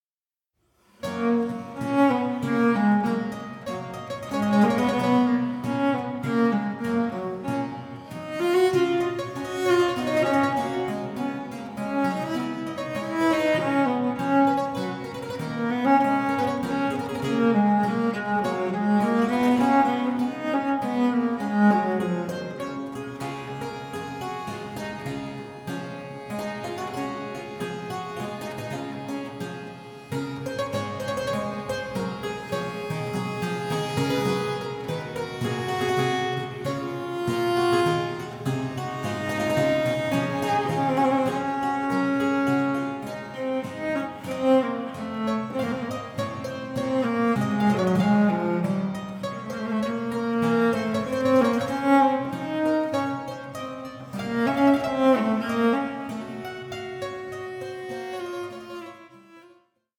five-string cello piccolo
clavichord